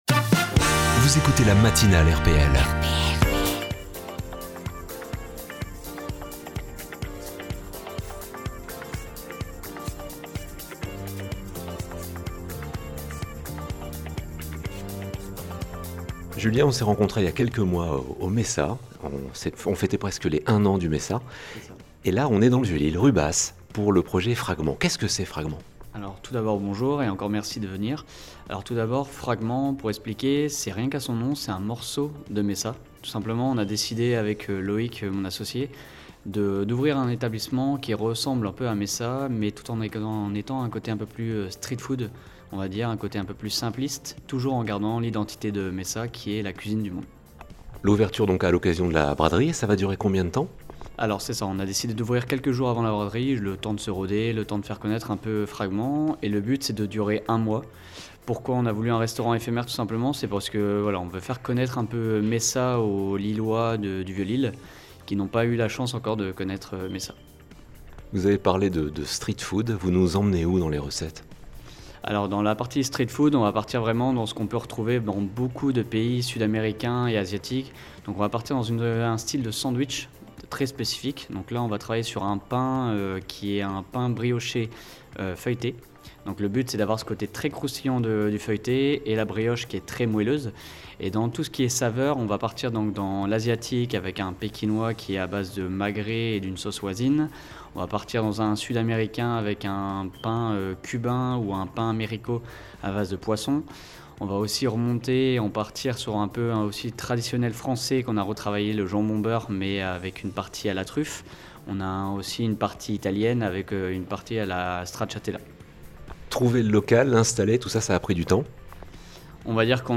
3. INTERVIEWS